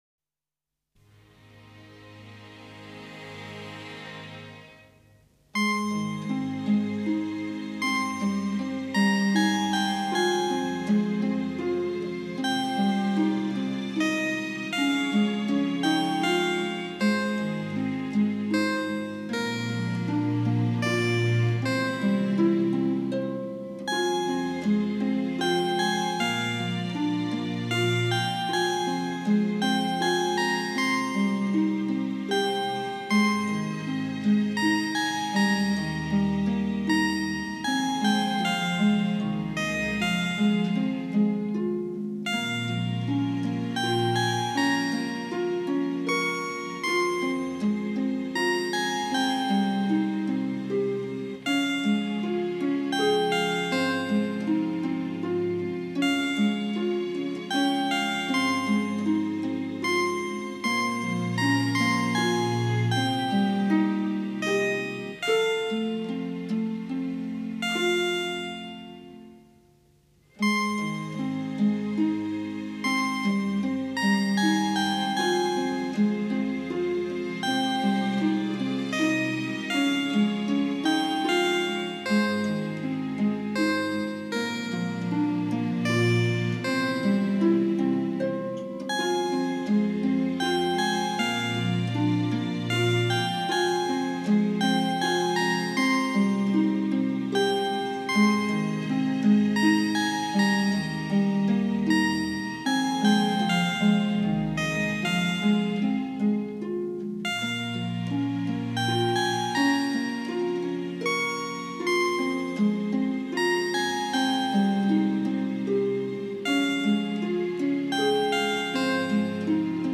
耳边传来澄静透明的声音，小女孩天真无邪的声音与乐器之间的和谐如同对话一样，听来安详愉快。